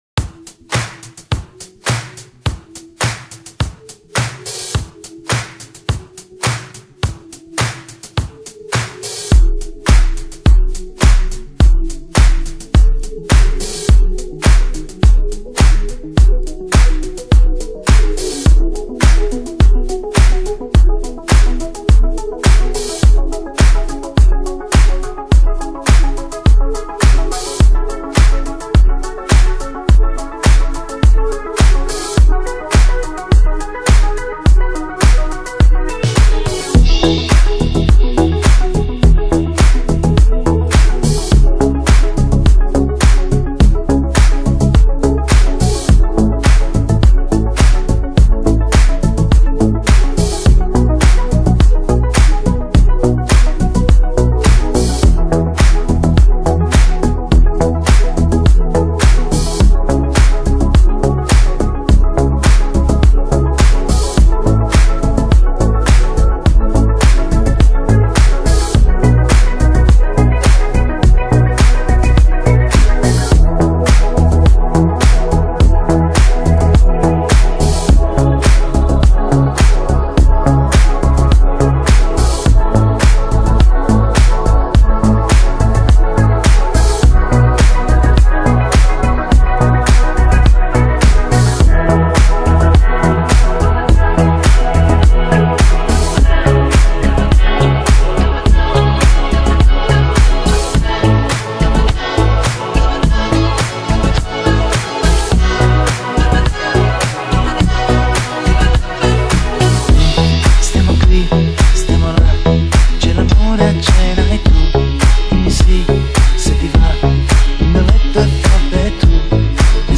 versione remix